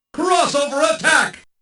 Unused voices